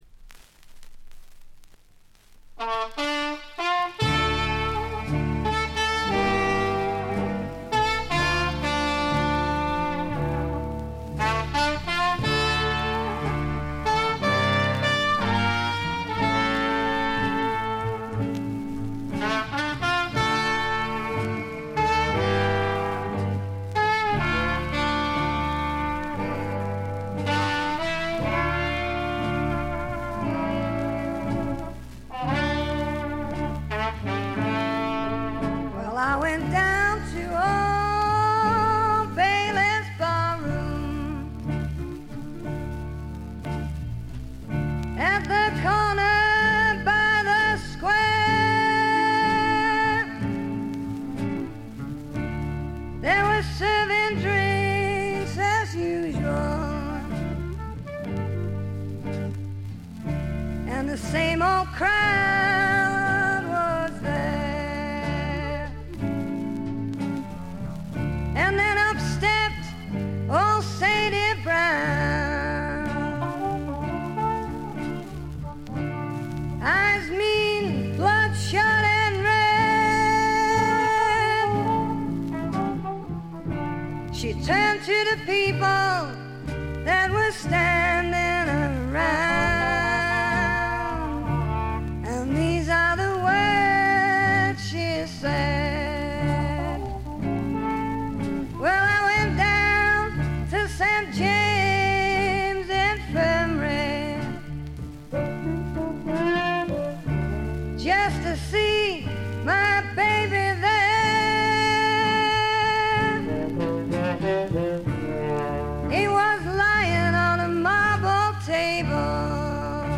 部分試聴ですが、ほとんどノイズ感無し。
試聴曲は現品からの取り込み音源です。